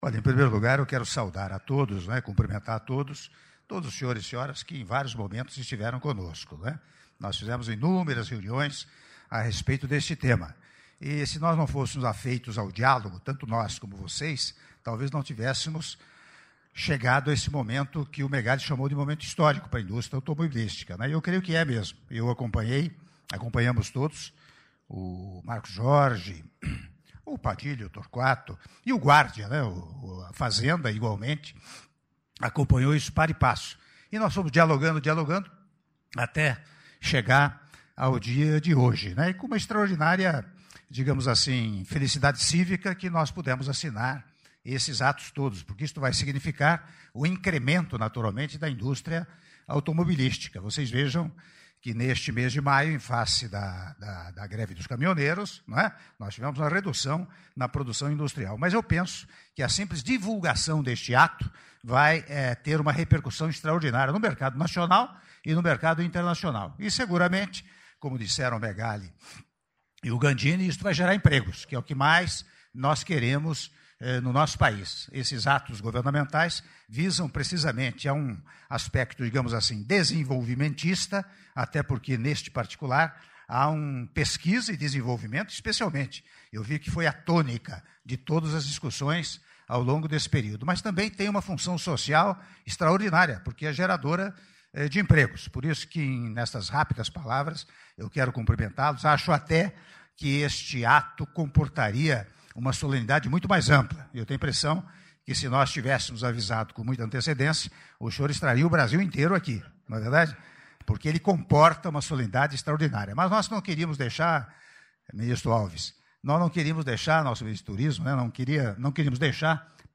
Áudio do discurso do Presidente da República, Michel Temer, durante Solenidade de Lançamento do Rota 2030 - Palácio do Planalto (02min40s) — Biblioteca